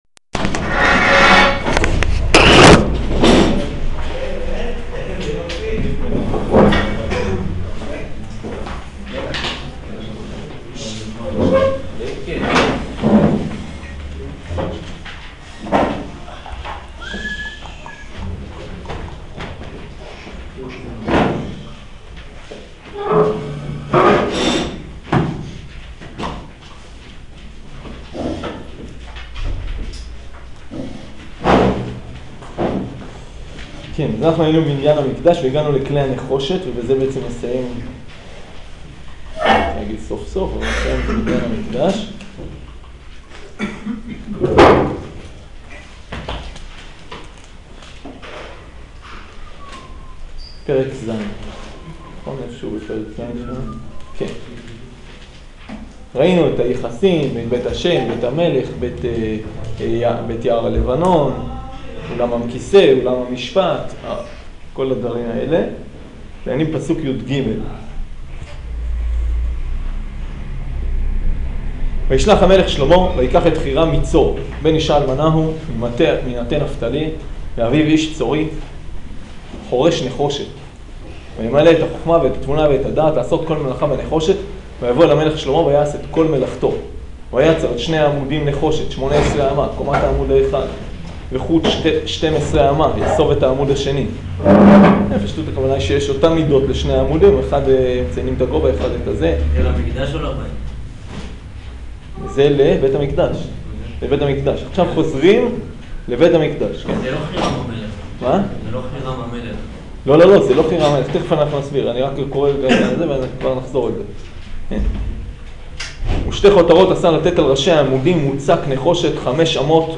שיעור פרק ז'